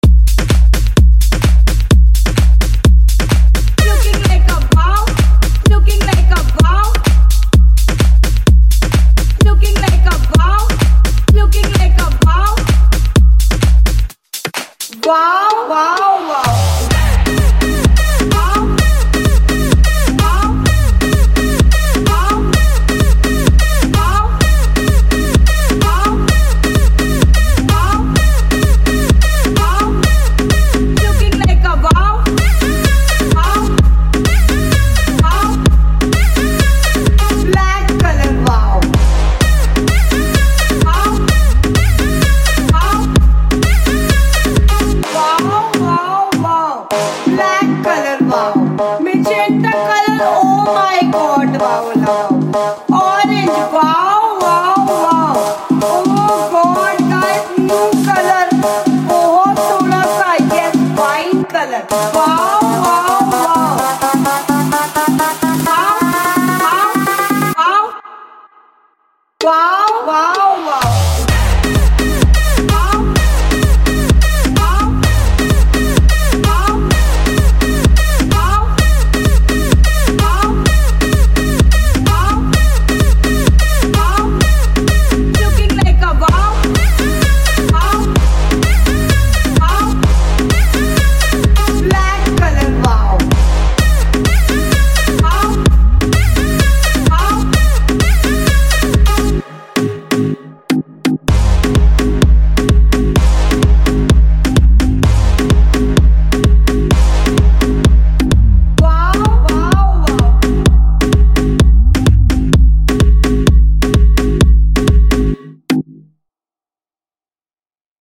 High quality Sri Lankan remix MP3 (3).